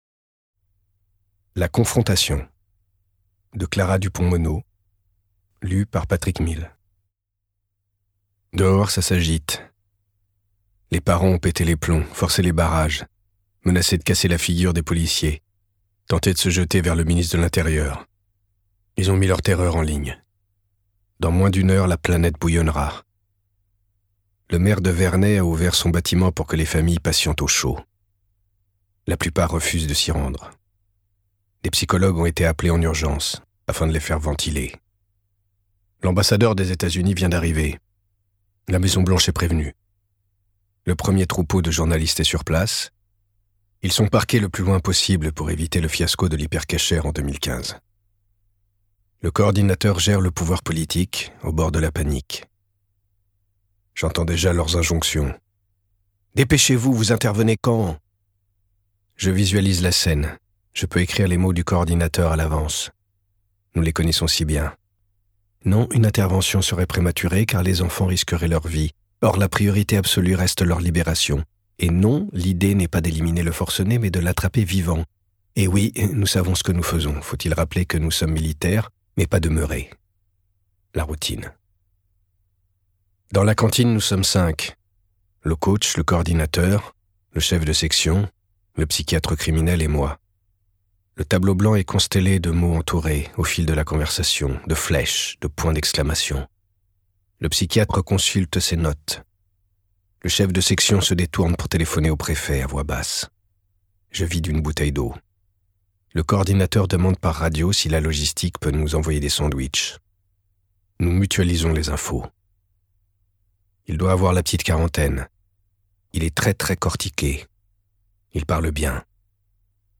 je découvre un extrait - La Confrontation de Clara Dupont-Monod, Patrick Mille
Interprétation humaine Durée : 03H14 × Guide des formats Les livres numériques peuvent être téléchargés depuis l'ebookstore Numilog ou directement depuis une tablette ou smartphone.